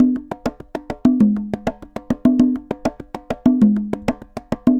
Congas_Baion 100_2.wav